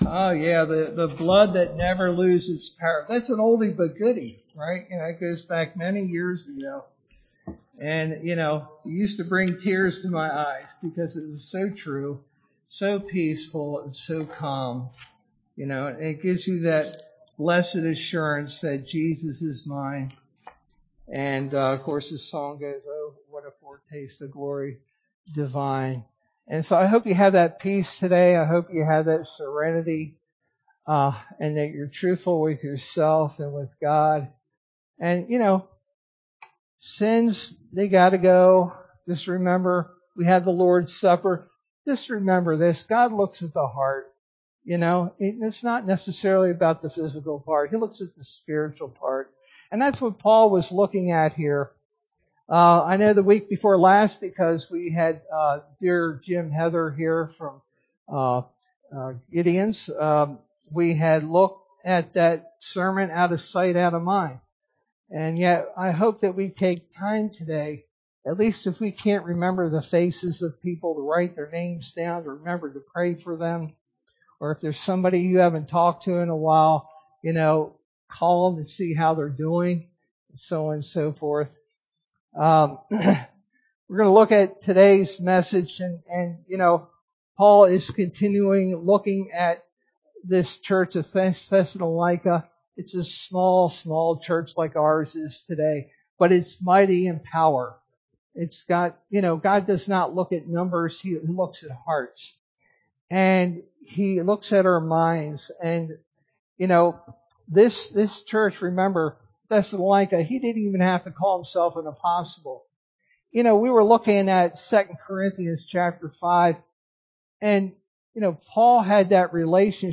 Sermon verse: 1 Thessalonians 3:1-13